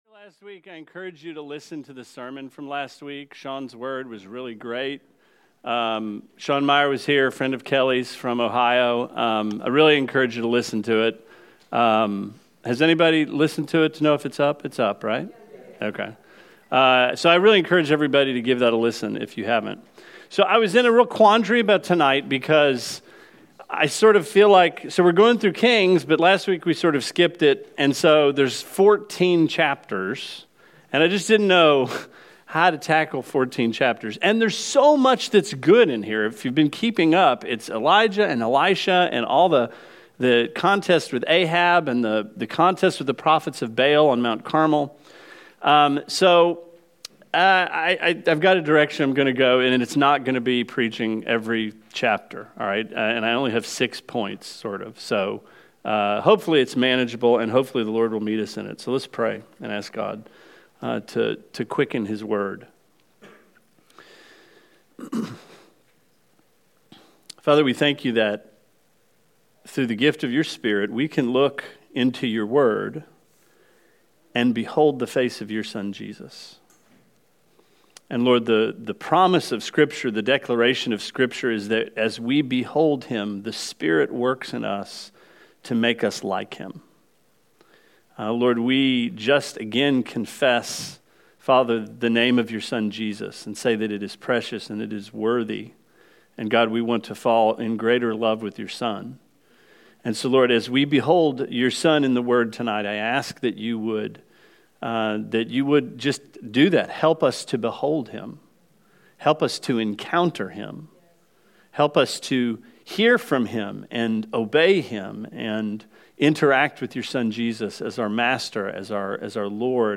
Sermon 08/10: Christ in 2 Kings